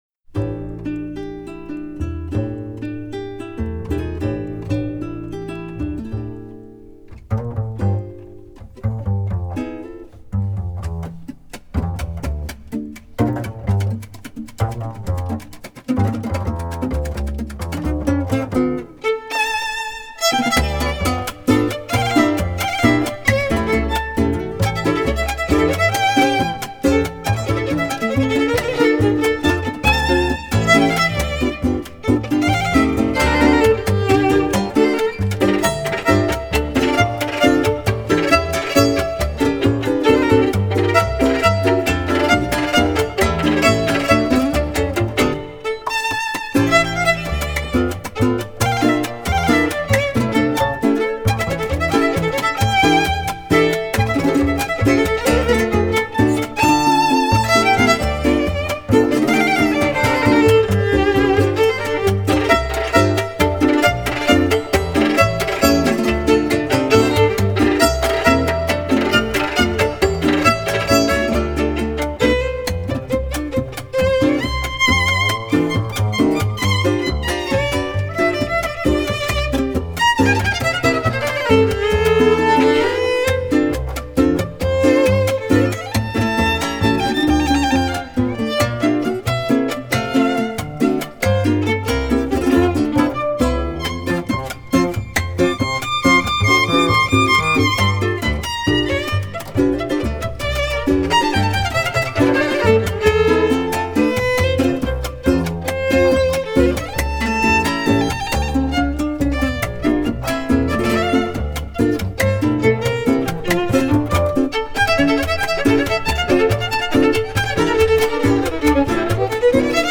Música latina
La música de América Latina